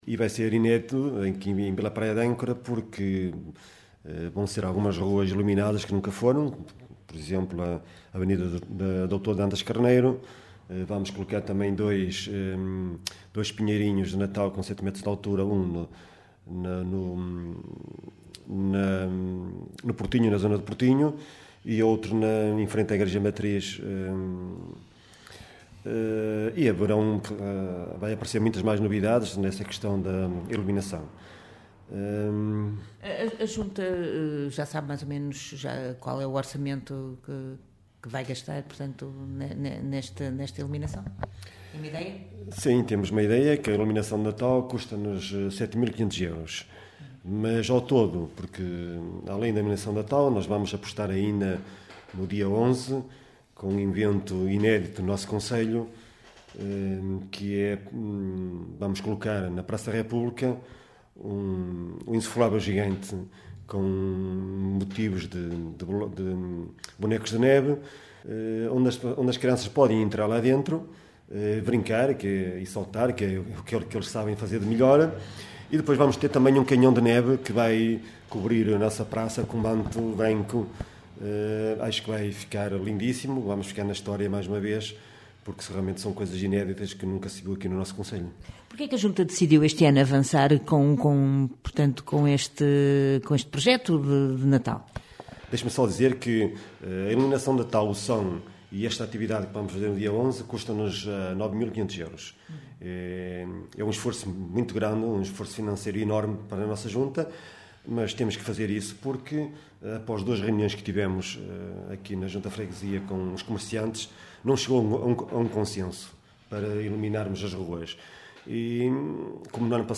À Rádio Caminha, o presidente da Junta, Carlos Castro, diz que a iluminação de Natal e a colocação do som vão custar 7,5 mil euros, um “forte investimento” para atrair mais visitantes